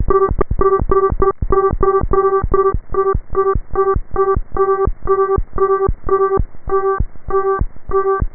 00014_Sound_slowbeeps.mp3